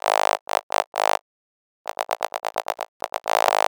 Broken Oscillator Ab 130.wav